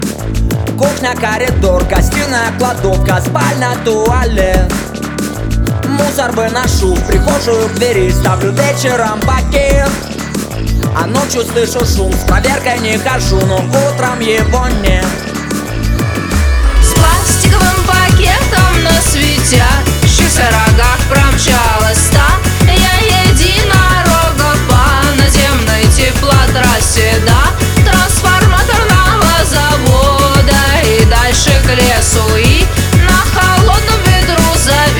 Rock Electronic